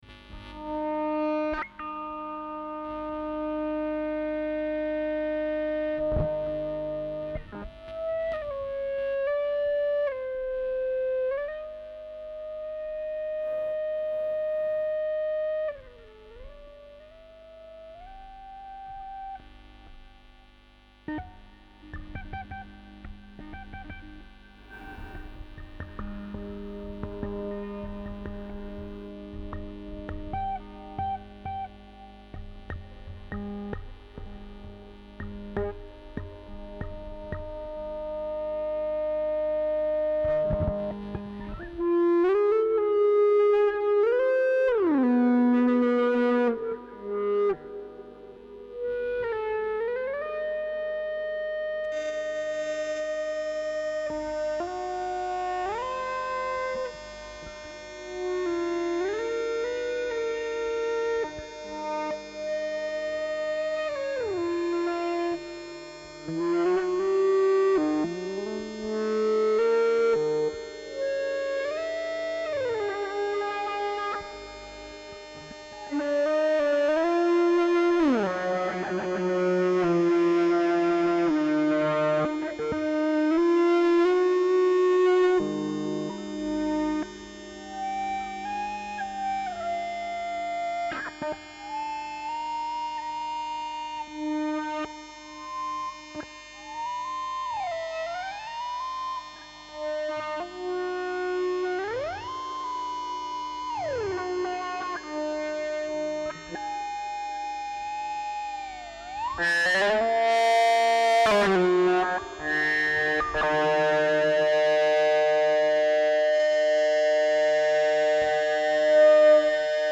ASCENSION GUITAR ascending-scaled 4-string electric guitar e-bowed solo w reverberation from two 18-20 meter tall hollow towers used as echo-chambers premiered at SLUSEN 20th of june 2019 Slusen Ascension.mp3